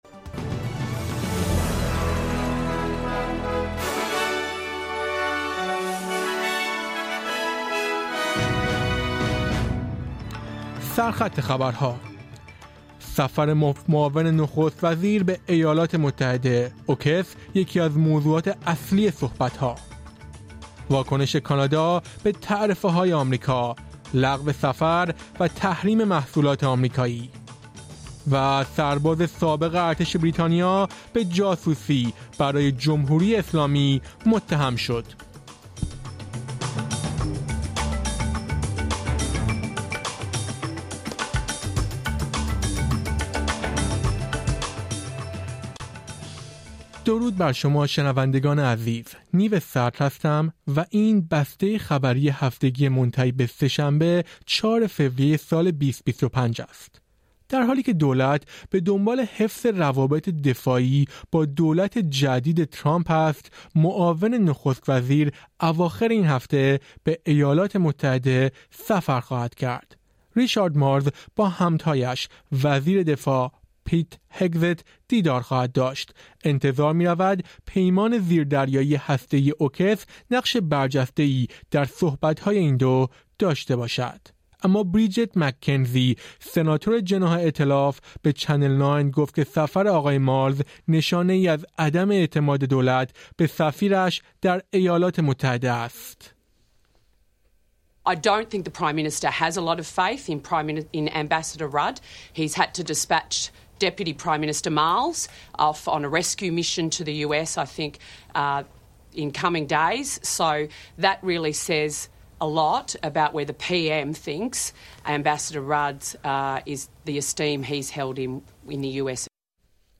در این پادکست خبری مهمترین اخبار منتهی به سه‌شنبه ۴ فوریه ارائه شده است.